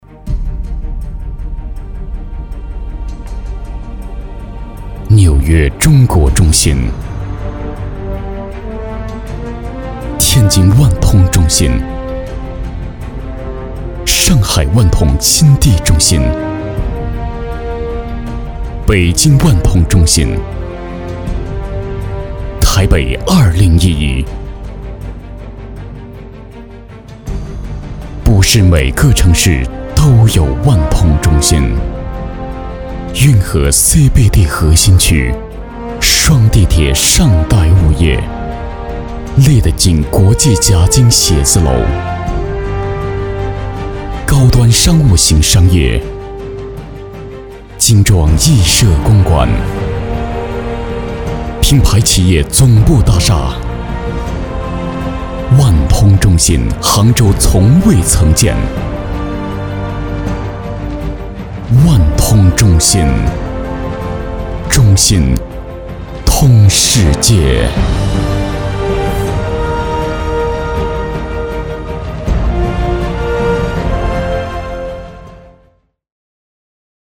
男国184年轻活力时尚配音-新声库配音网
男国184_广告_地产_万通中心.mp3